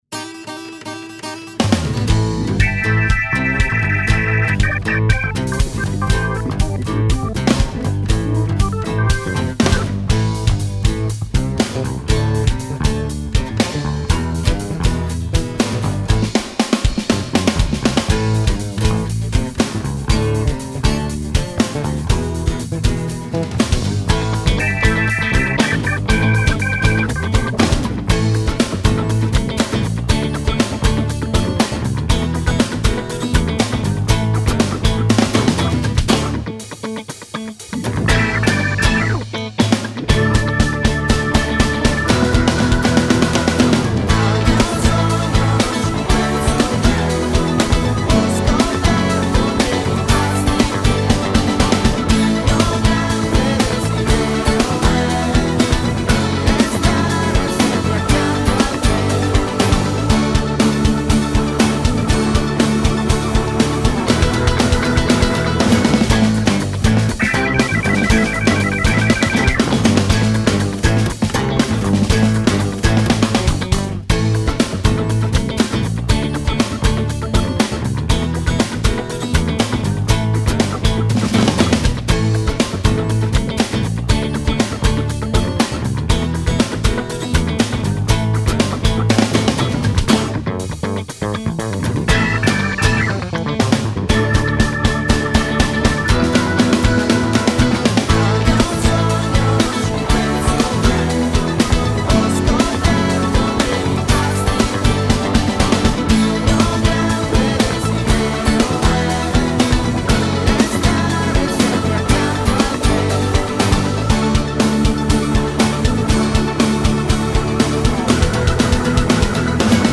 Base musicale